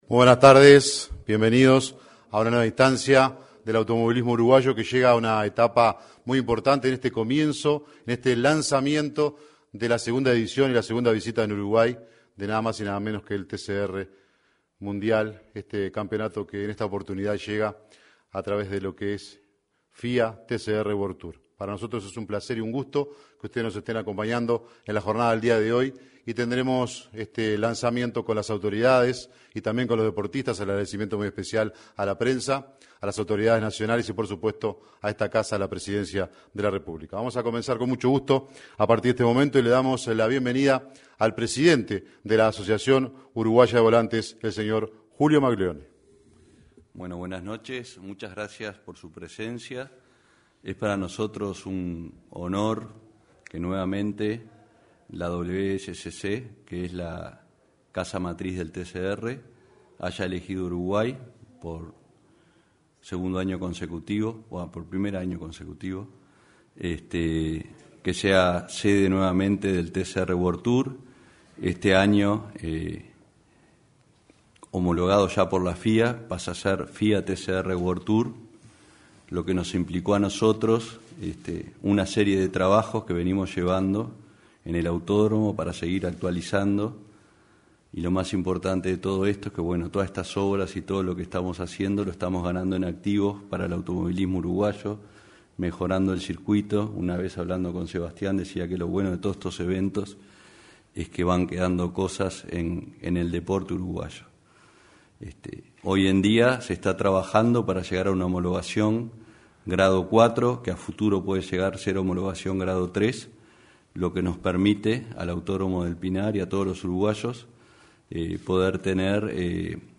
Presentación del TCR World Tour South America 03/07/2024 Compartir Facebook X Copiar enlace WhatsApp LinkedIn En el salón de actos de la Torre Ejecutiva se realizó la presentación del TCR World Tour South America.